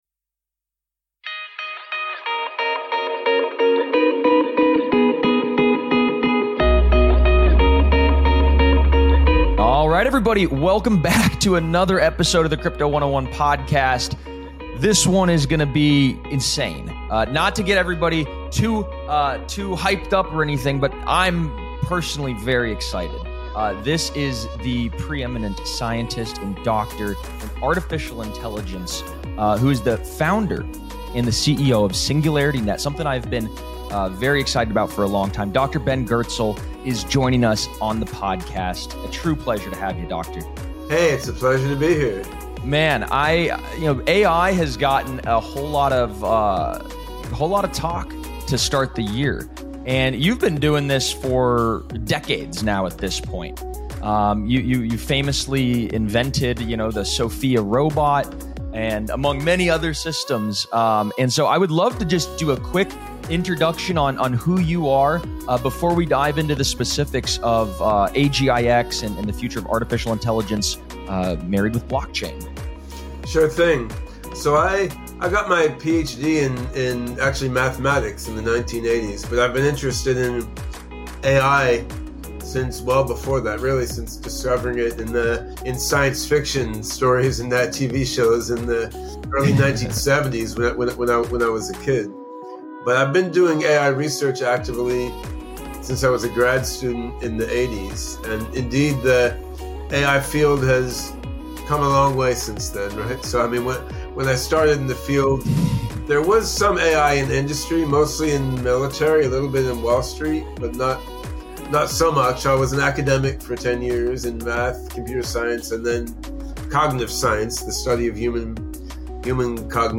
We cover all the need to know topics of how we can leverage A.I today and look out into the future of what it could become. From assisting people’s work to taking over some jobs, this is a fascinating conversation from someone who has lived in this world his entire professional career.